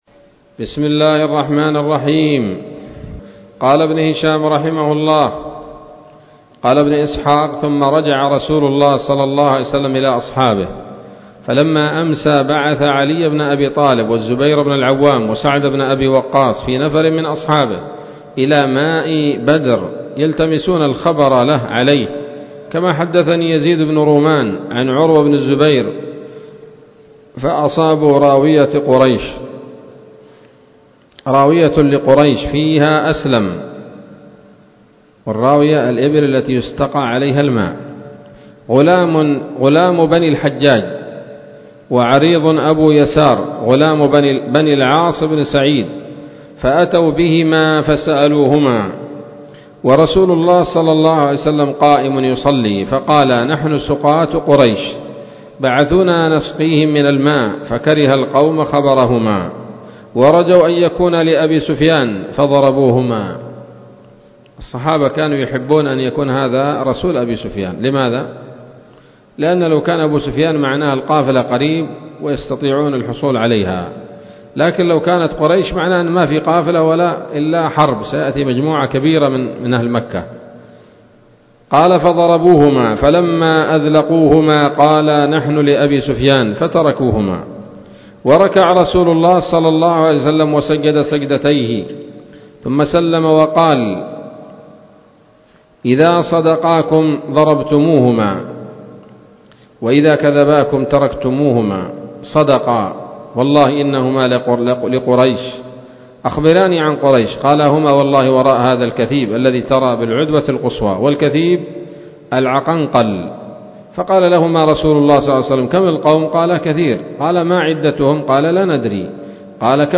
الدرس الحادي عشر بعد المائة من التعليق على كتاب السيرة النبوية لابن هشام